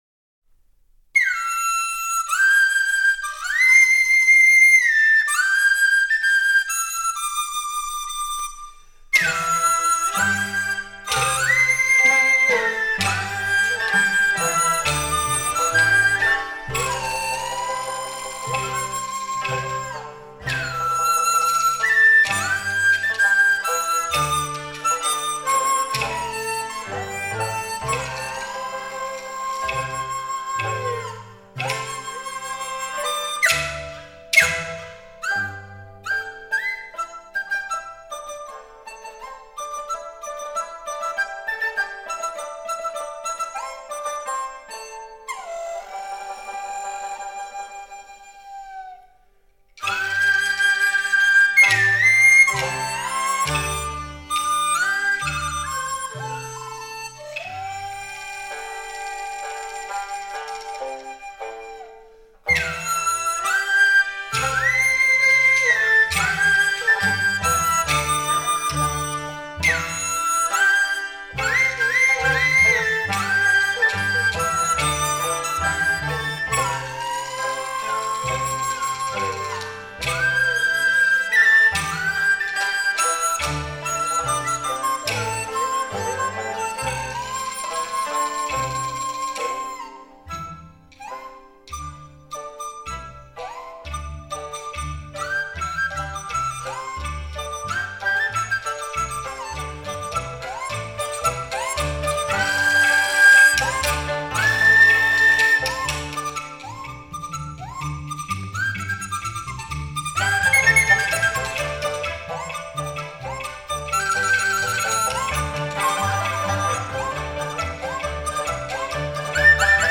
中国吹管乐
梆笛